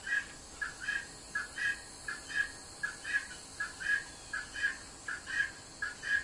豚鼠 ( 雌性 )
描述：雌性天竺鼠的叫声。雄性天竺鼠用单音节呼叫，但雌性天竺鼠可以用单音节和双音节呼叫。